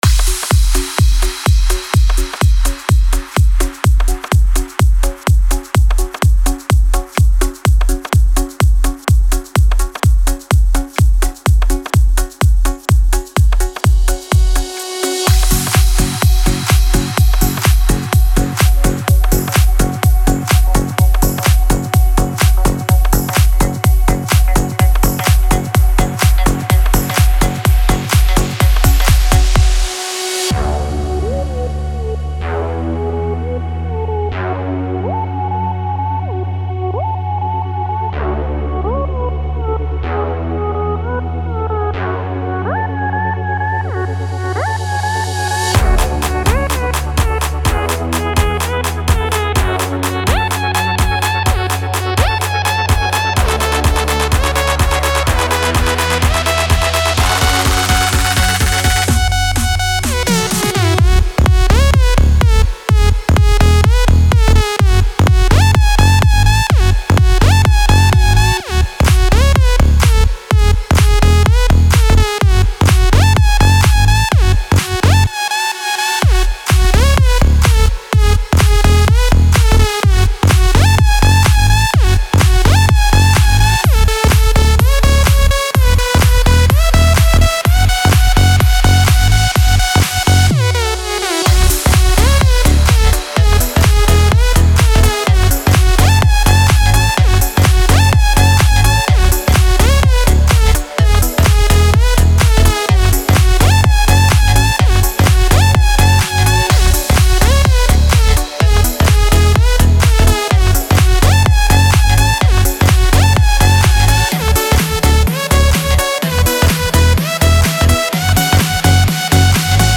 Она находится в категории Клубная музыка.